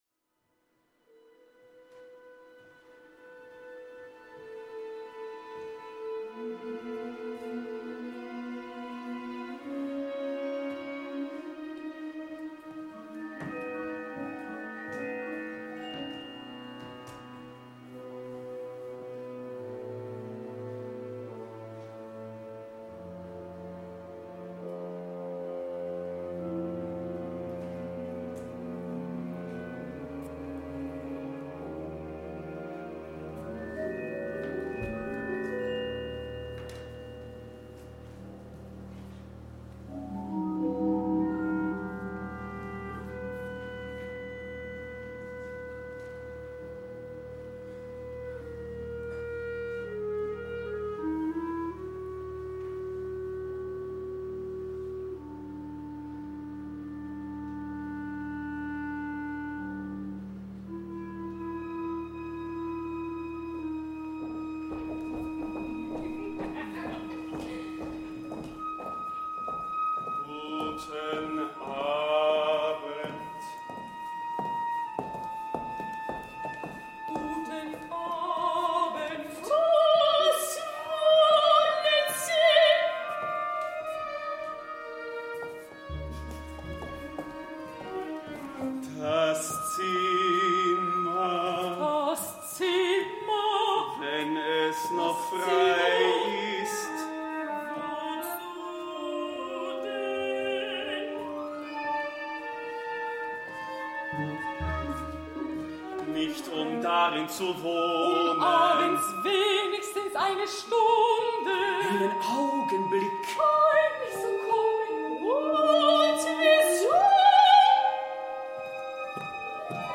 two one-act operas
mezzo-soprano
alto